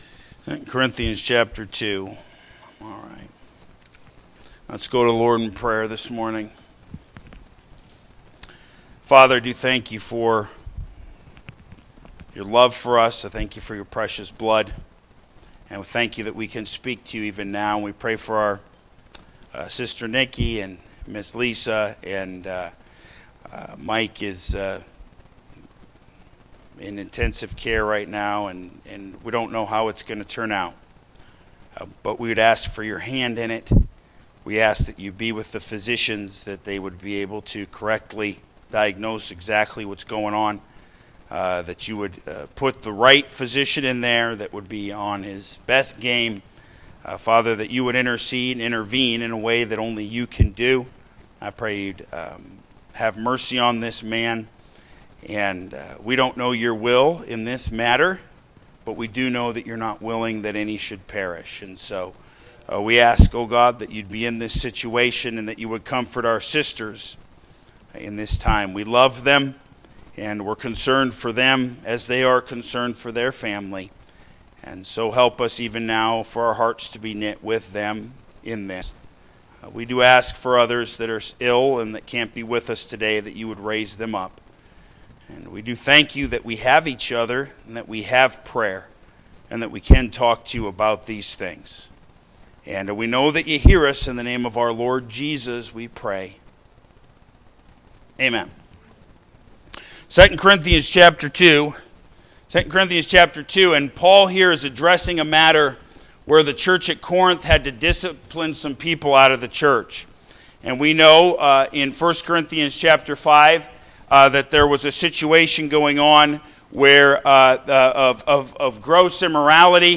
Main Service